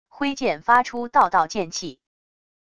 挥剑发出道道剑气wav音频